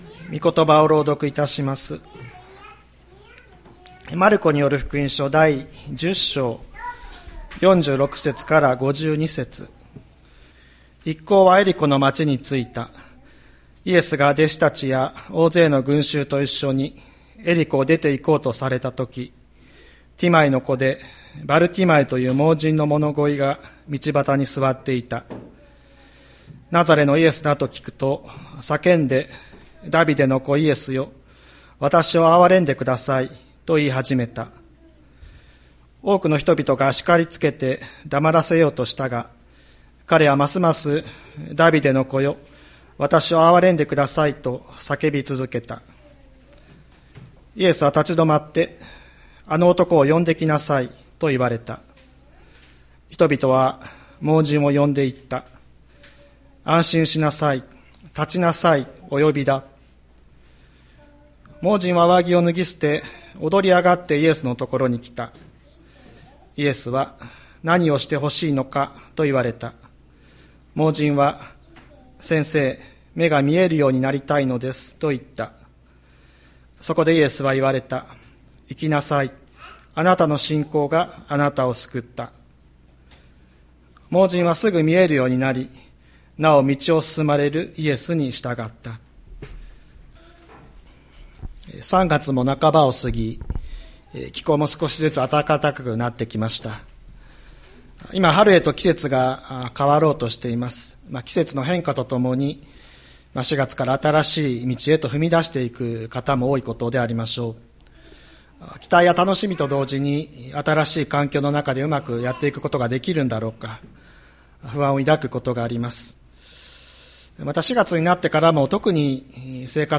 2023年03月19日朝の礼拝「安心して立とう」吹田市千里山のキリスト教会